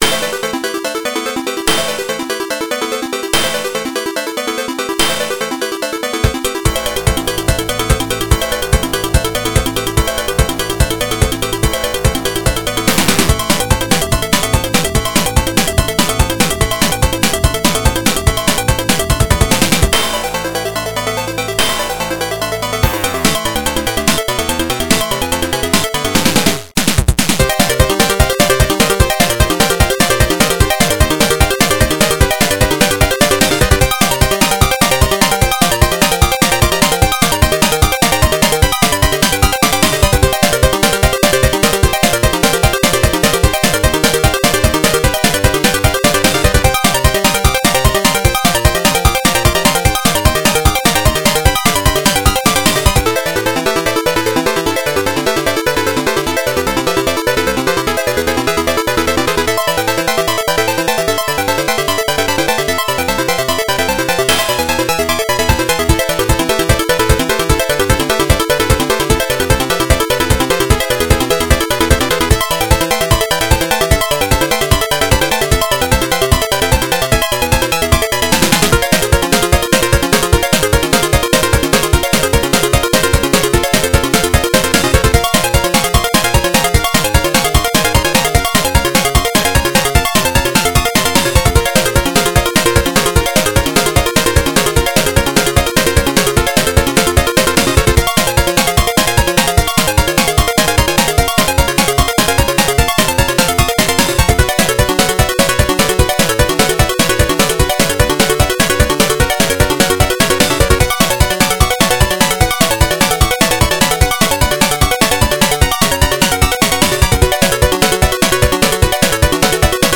原游戏FM86版，由PMDPlay导出。